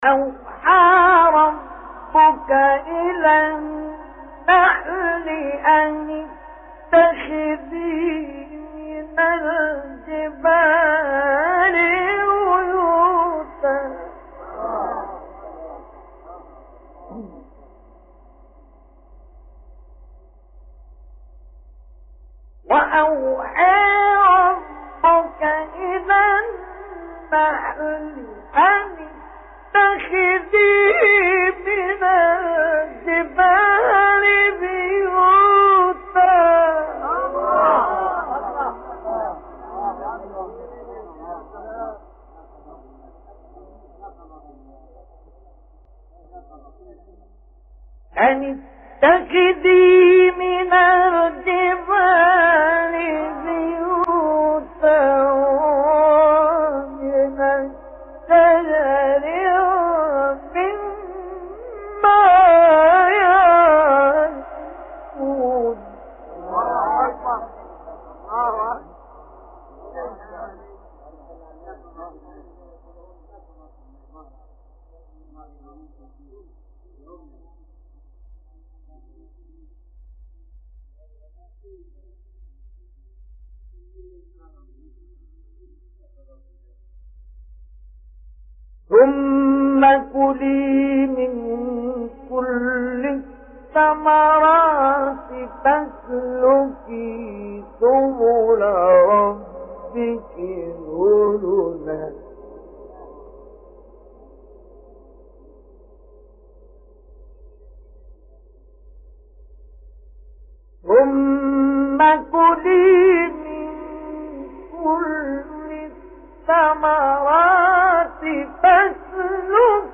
تلاوت 68-69 سوره نحل مصطفی اسماعیل | نغمات قرآن
استاد: مصطفی اسماعیل مقام : بیات